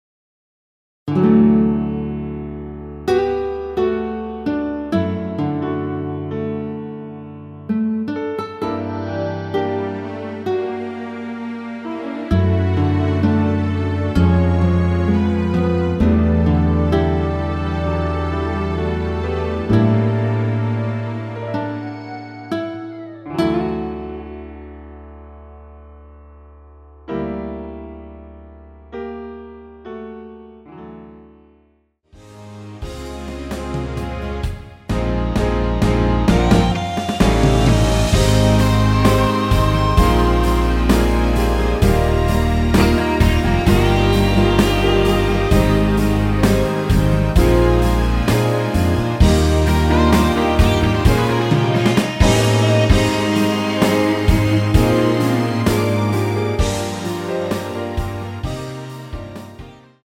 내린 MR 입니다.
◈ 곡명 옆 (-1)은 반음 내림, (+1)은 반음 올림 입니다.
앞부분30초, 뒷부분30초씩 편집해서 올려 드리고 있습니다.